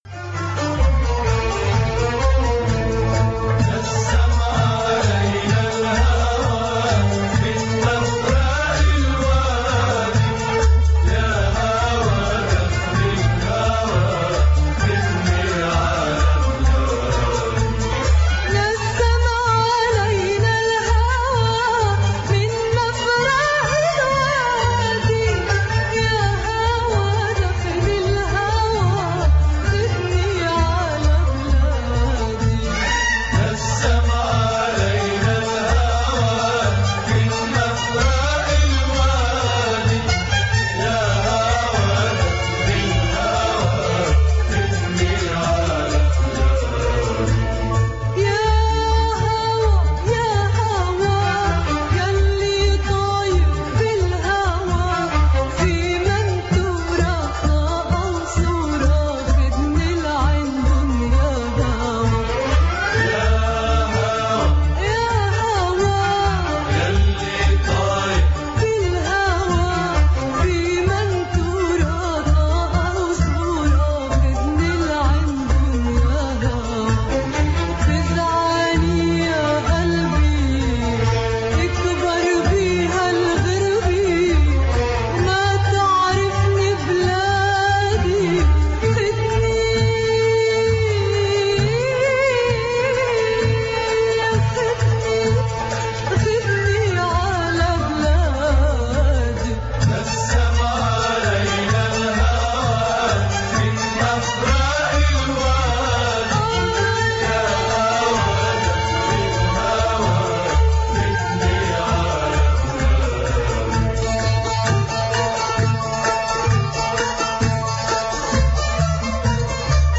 Arab Voices Radio Talk Show - 2019 Archives